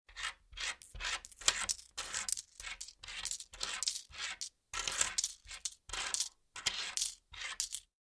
counting-coins_24734.mp3